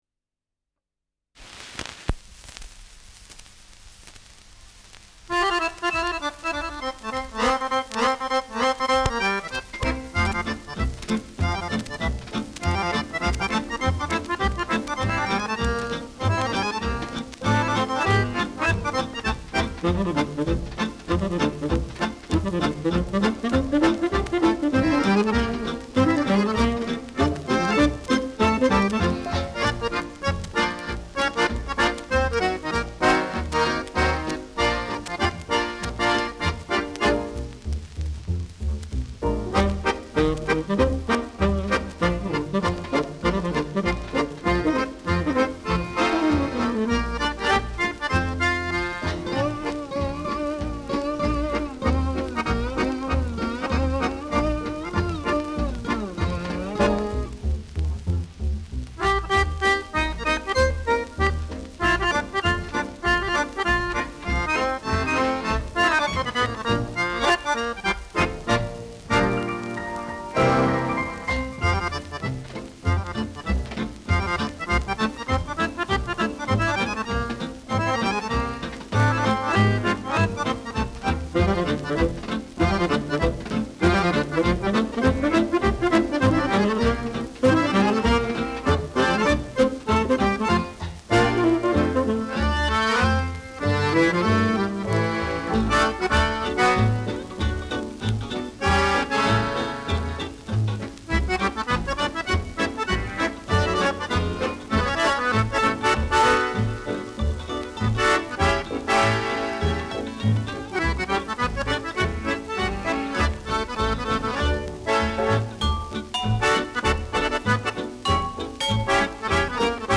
Tanz-Intermezzo
Schellackplatte
[unbekanntes Ensemble] (Interpretation)